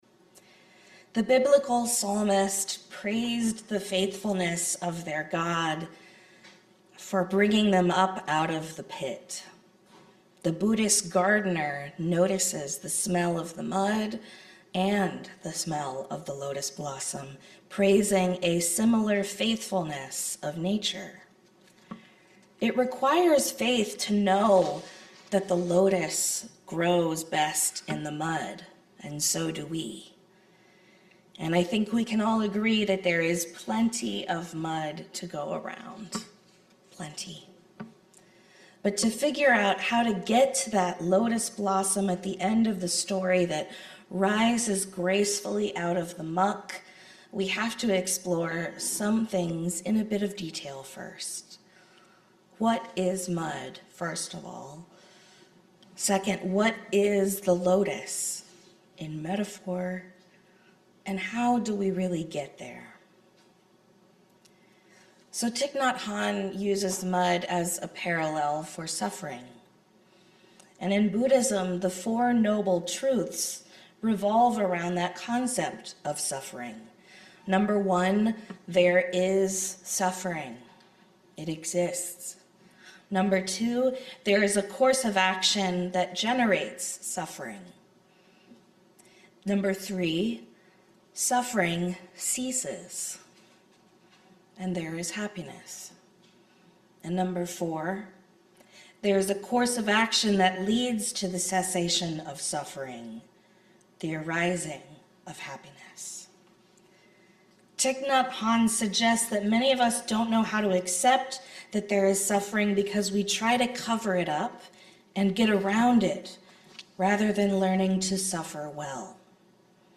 This sermon explores the metaphorical relationship between suffering and growth, using the Buddhist imagery of a lotus rising from the mud.